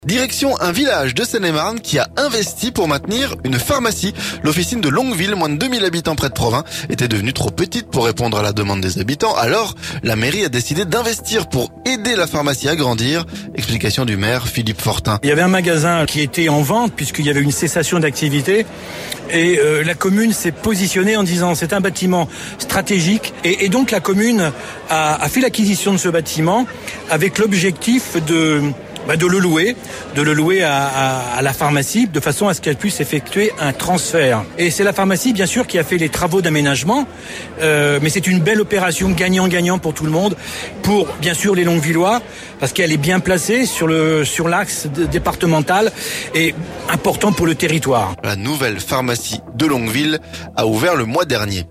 LONGUEVILLE - La mairie a aidé la pharmacie à s'agrandir, notre reportage
L'officine de Longueville, moins de 2.000 habitants près de Provins, était devenue trop petite pour répondre à la demande des habitants. Alors la mairie a décidé d'investir pour aider la pharmacie à grandir. Explications du maire Philippe Fortin.